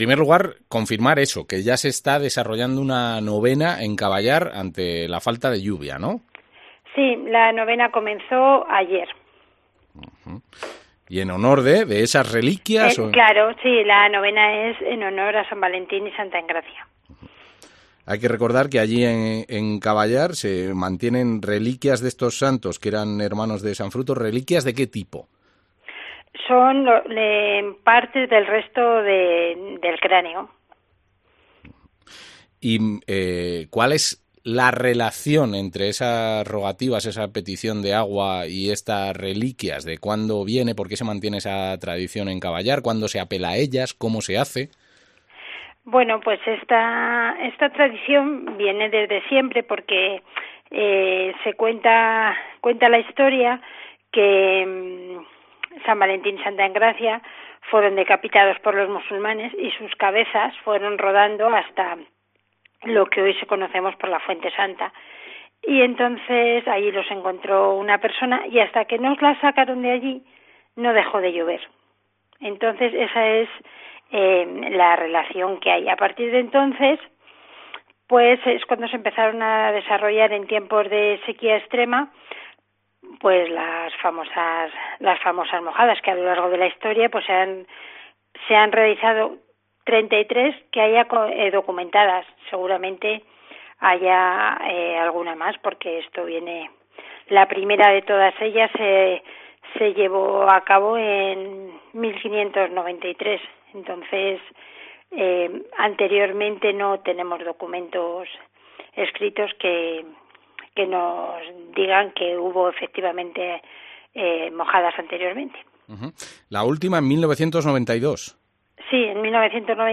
Susana Gómez, alcaldesa de Caballar